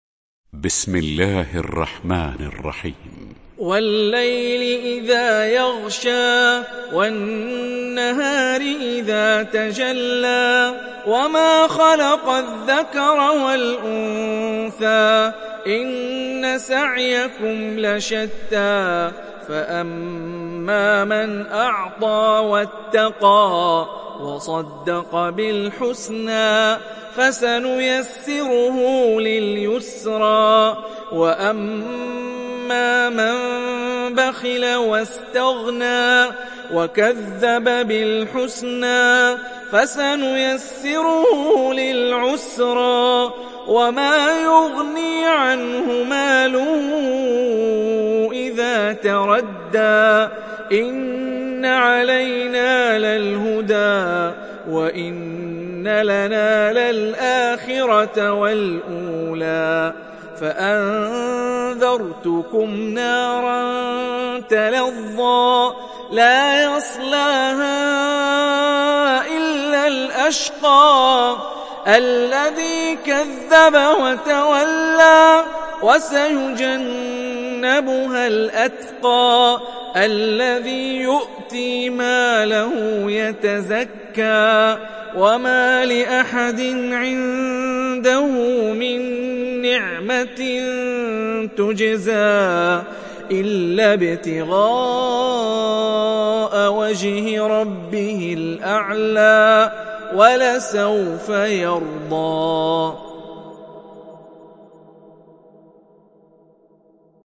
تحميل سورة الليل mp3 بصوت هاني الرفاعي برواية حفص عن عاصم, تحميل استماع القرآن الكريم على الجوال mp3 كاملا بروابط مباشرة وسريعة